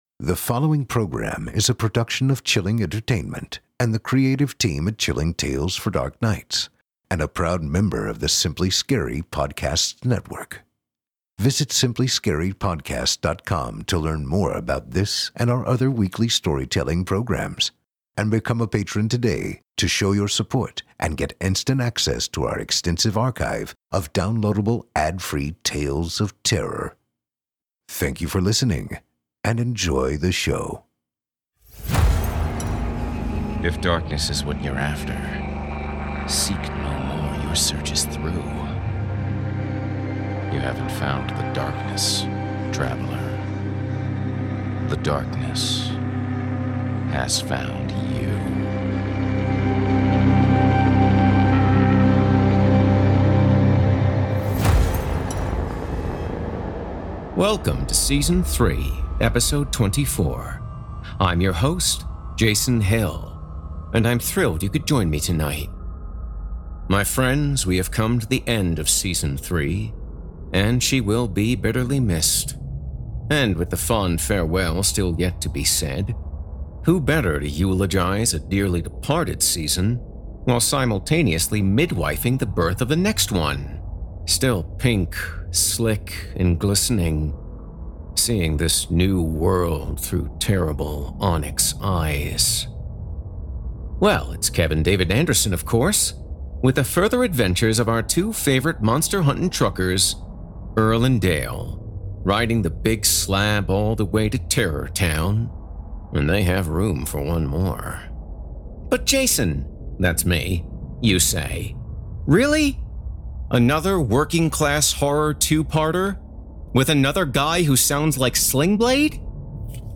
A Horror Anthology and Scary Stories Series Podcast